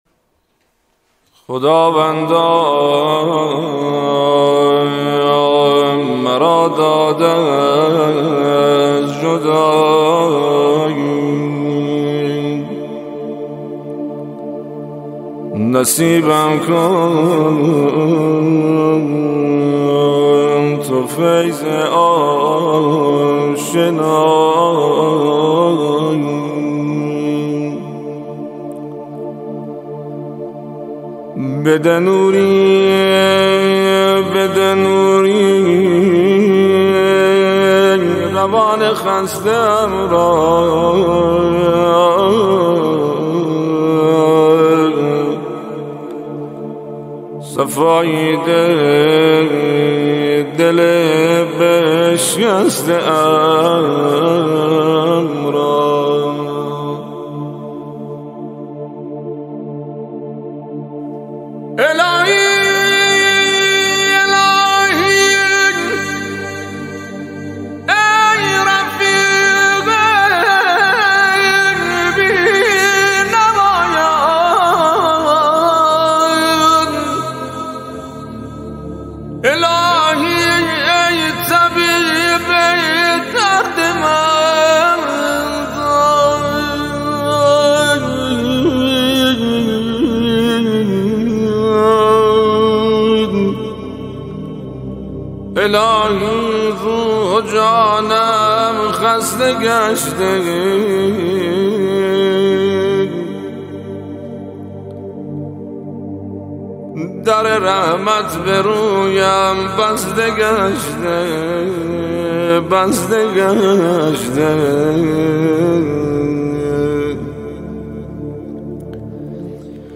مناجات با خدا